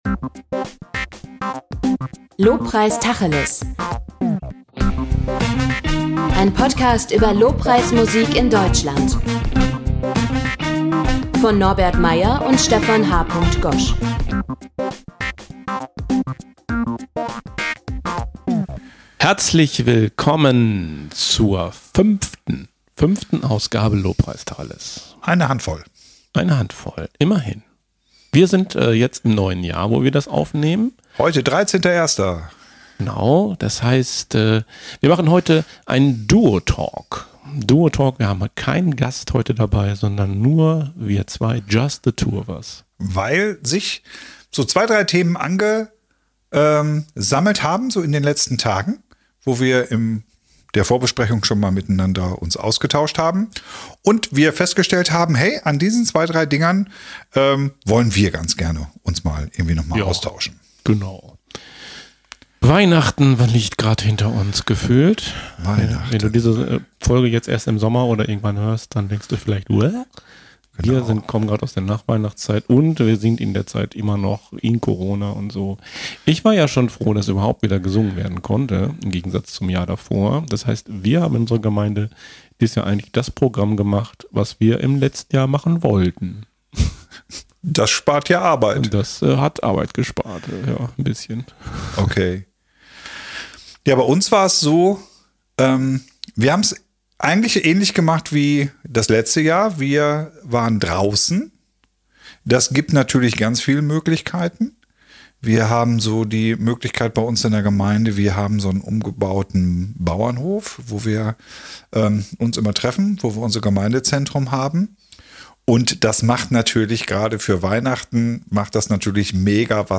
Januar 2022 Nächste Episode download Beschreibung Teilen Abonnieren Heute ein Duo Talk unter dem Motto: Was sonst noch so war. Verschiedene Themen, die uns z.Zt. auf dem Herzen liegen.